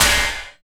50.07 SNR.wav